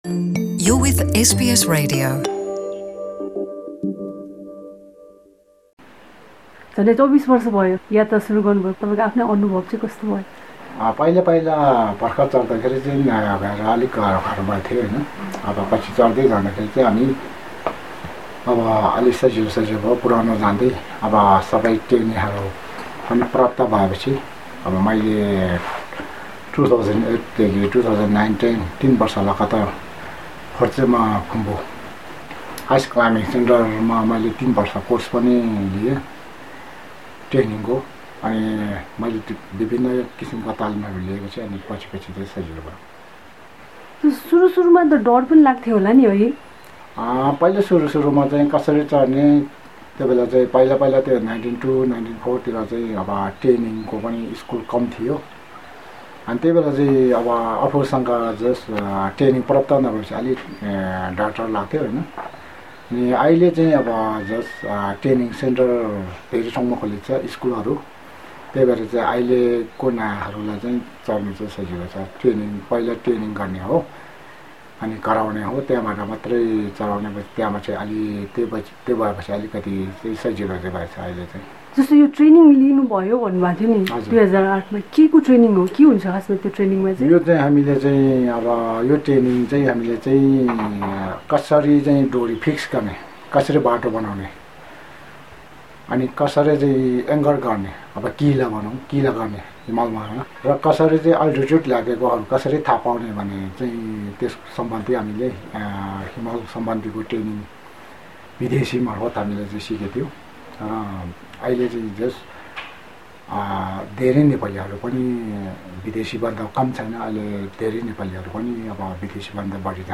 सर्वोच्च शिखर सगरमाथाको सफल आरोहणबारे उनले एसबिएस नेपालीसँग गरेको कुराकानी: